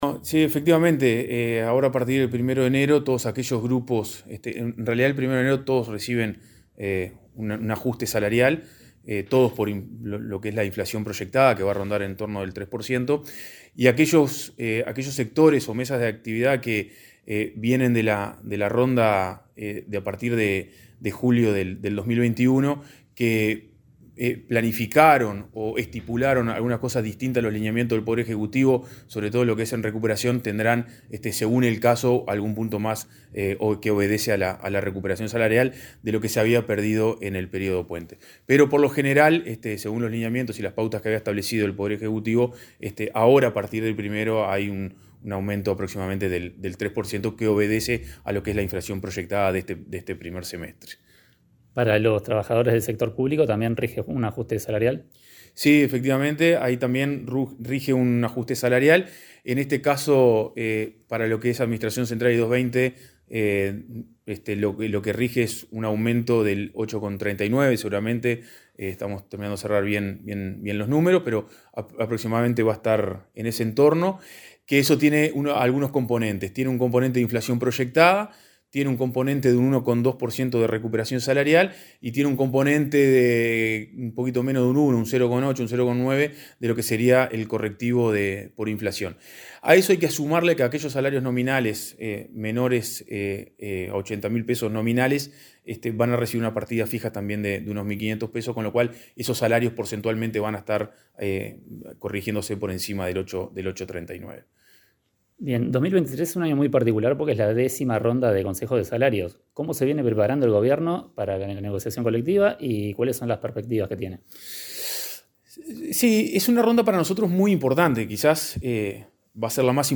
Entrevista al director nacional de Trabajo, Federico Daverede